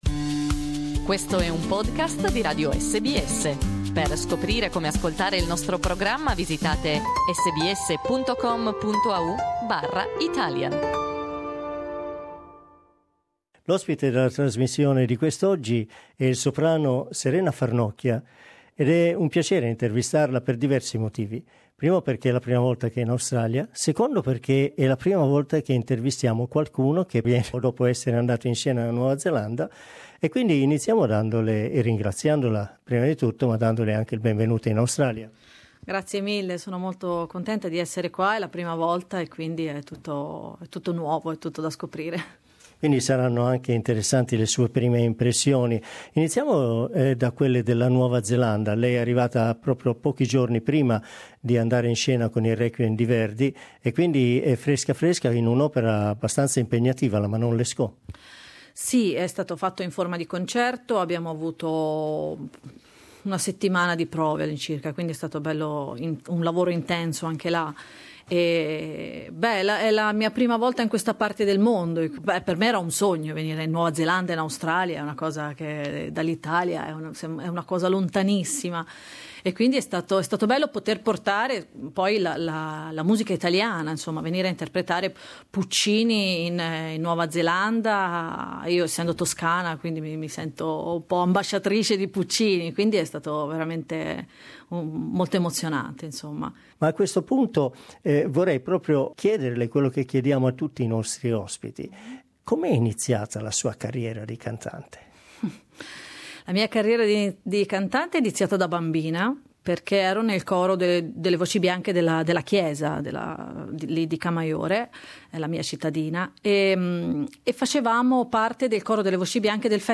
L'abbiamo incontrata ed intervistata per parlare della sua carriera e del suo viaggio in Australia.